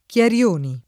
Chiarioni [ k L ar L1 ni ] cogn.